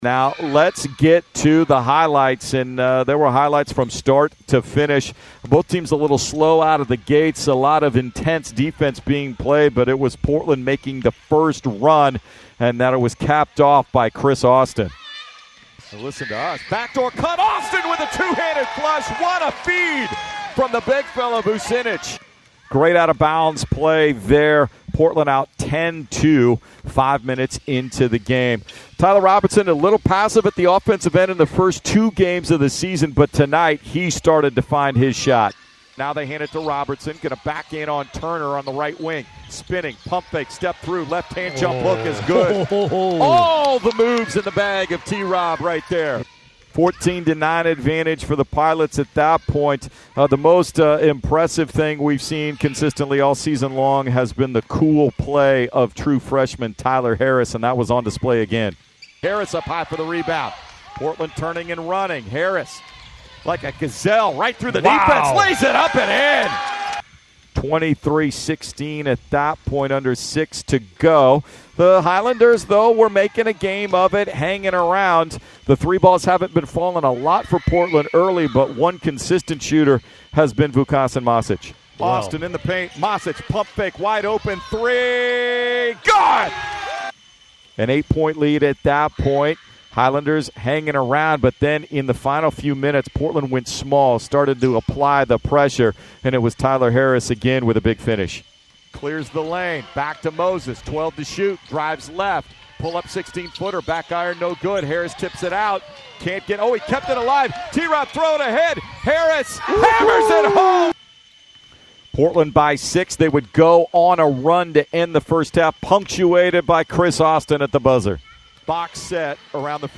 UC Riverside Radio Highlights